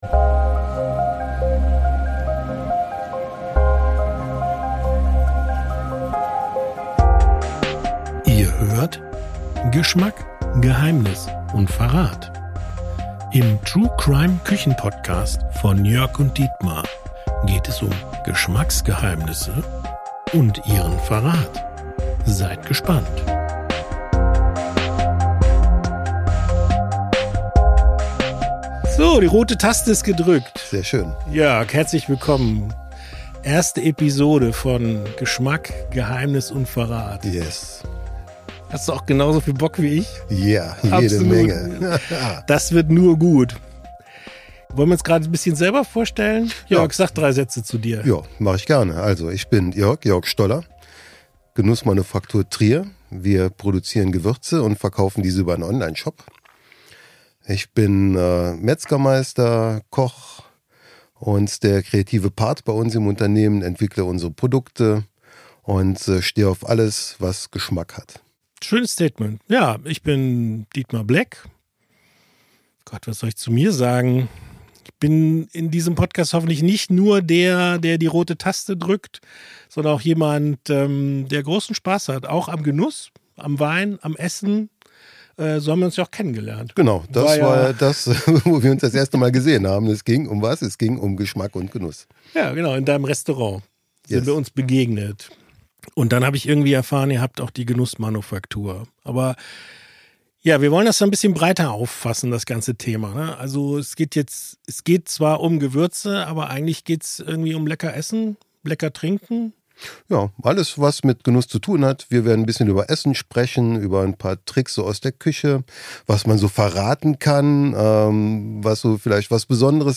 Ein entspannter Talk zwischen einem Könner und einem Kenner.